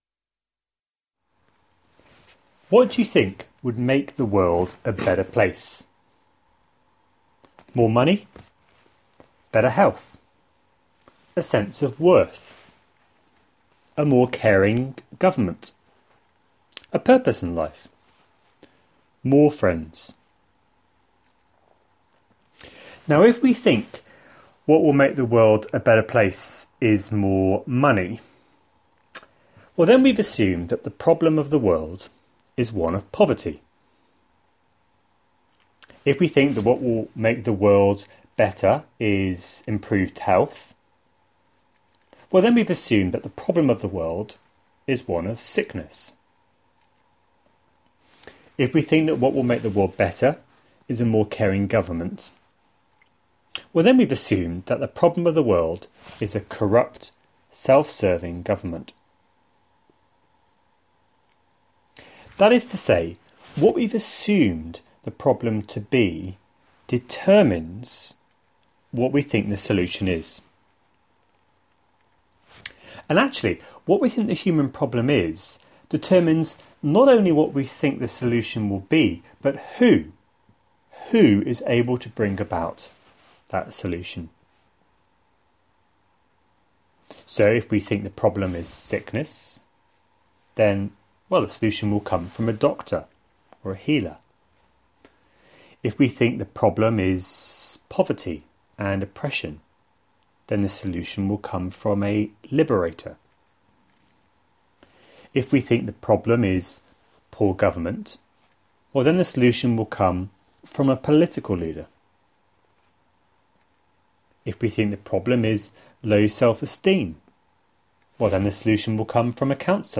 A sermon preached on 7th February, 2016, as part of our The God who makes himself known series.